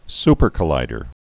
su·per·col·lid·er
(spər-kə-līdər)